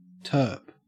Ääntäminen
Ääntäminen AU GA : IPA : /tɝp/ RP : IPA : /tɜːp/ Haettu sana löytyi näillä lähdekielillä: englanti Käännöksiä ei löytynyt valitulle kohdekielelle.